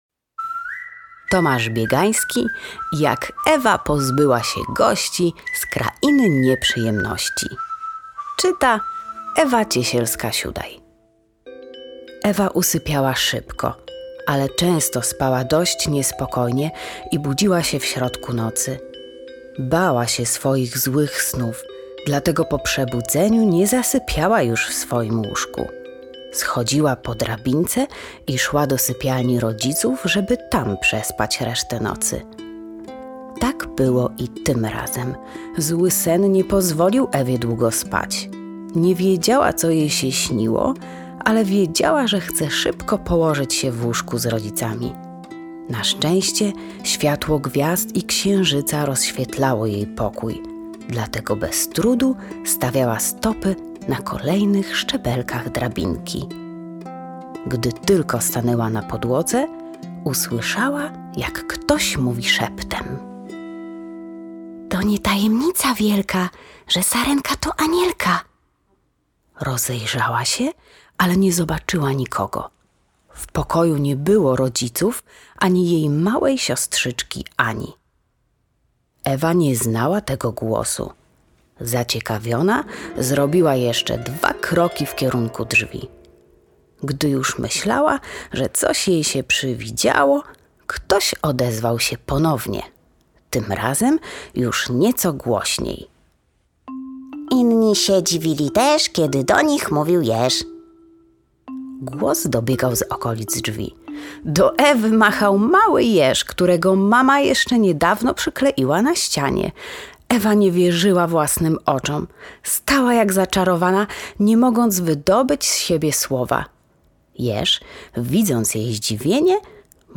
Fragment audiobooka